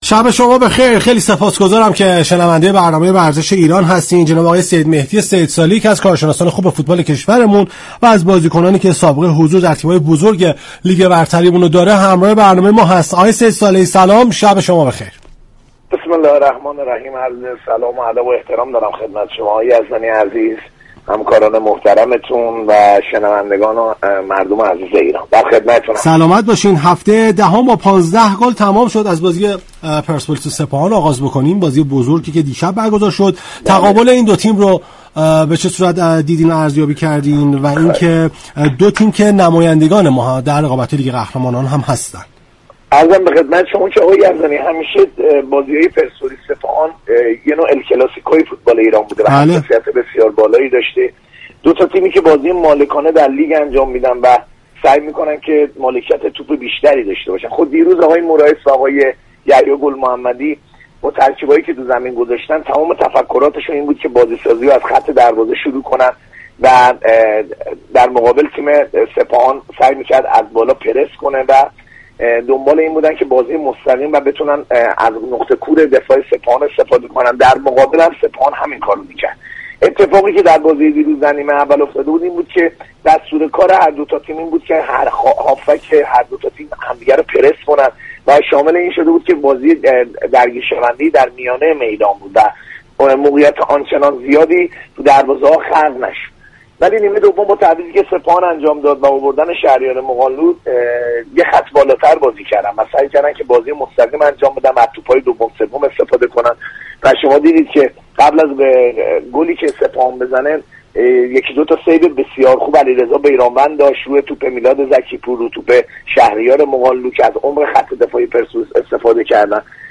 كارشناس فوتبال
گفت و گو